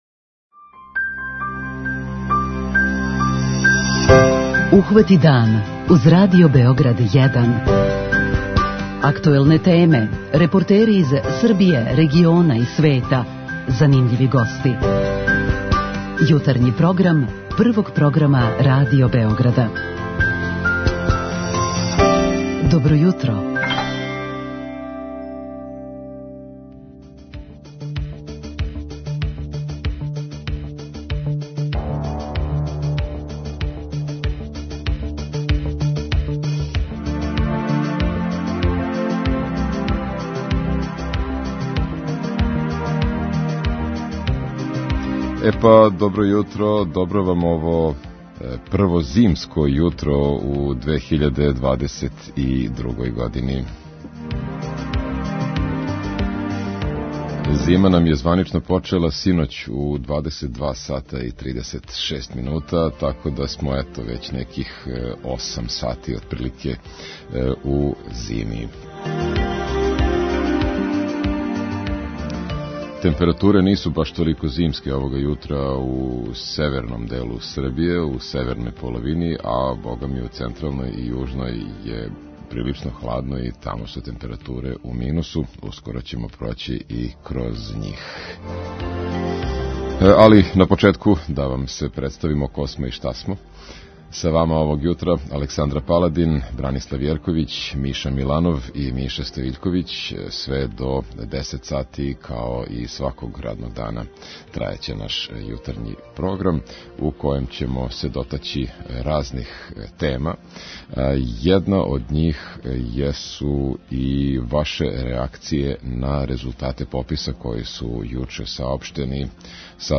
Добили смо прве резултате пописа који показују да се број становника у Србији за последњих десет година смањио за скоро пола милиона! Тим поводом питаћемо слушаоце како реагују на ову вест и шта предлажу као могуће решење.
Најавићемо једну новину која ступа на снагу 25. децембра, а то је увођење енергетског пасоша за нове аутомобиле. Шта то значи у пракси, да ли се односи и на половне аутомобиле или само на нове, разјасниће нам стручни саговорник.